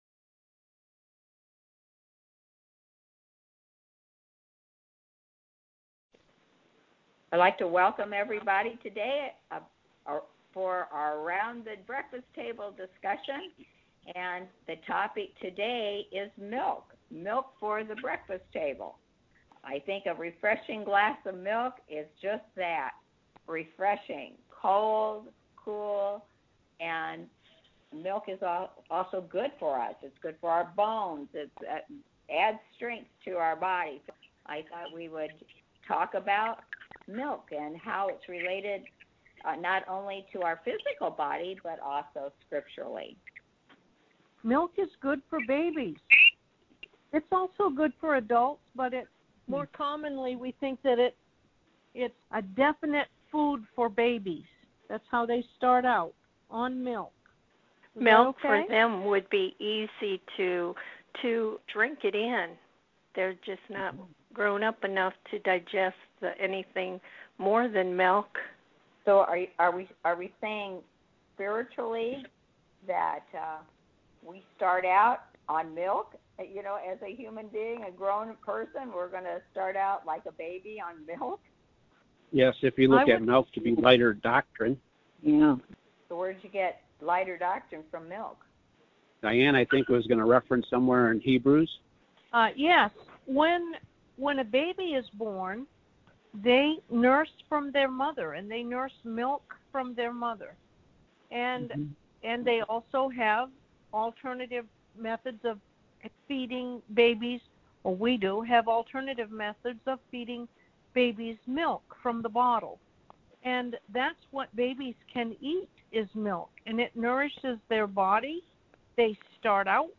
Conference-Recording-Ref-160-Milk-Edited.mp3